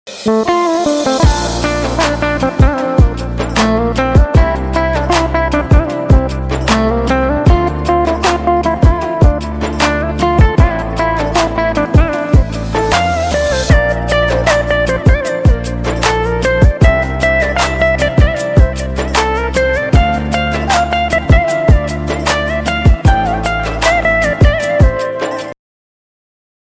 • Качество: 320, Stereo
спокойные
без слов
красивая мелодия
инструментальные
Просто приятная мелодия без слов